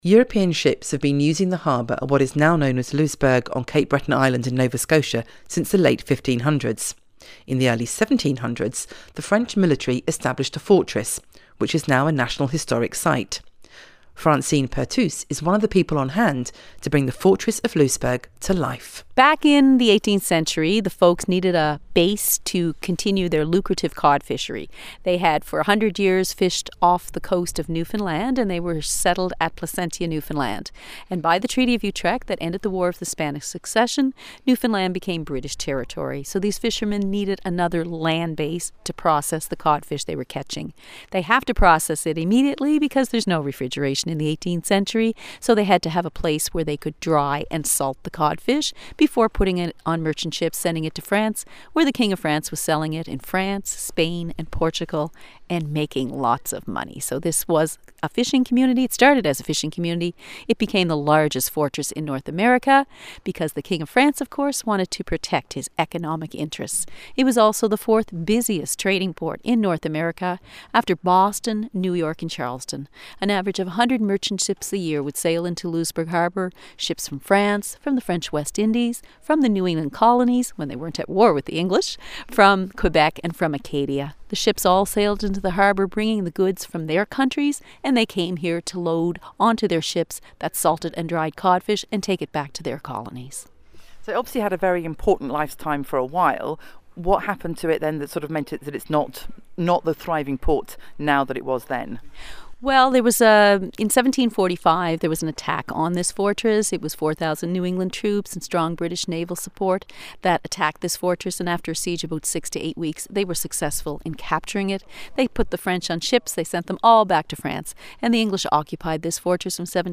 AUDIO TRAVEL